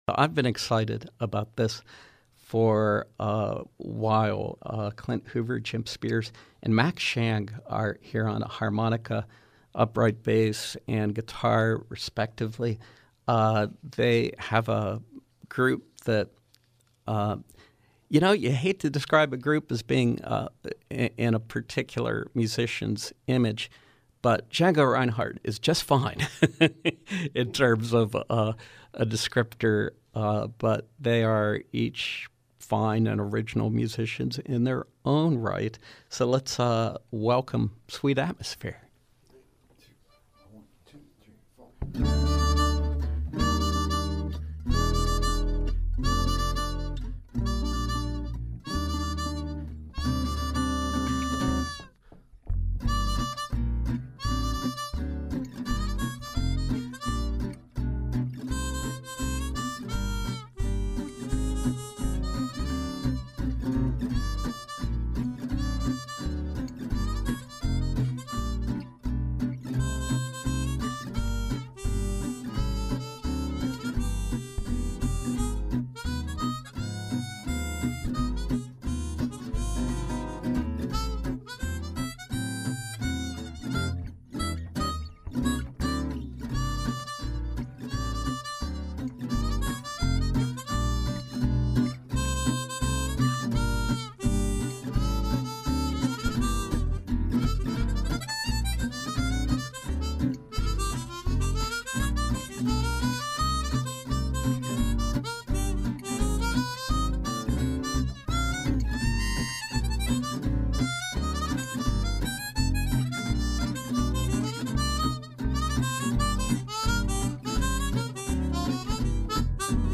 Gypsy Jazz, European Swing trio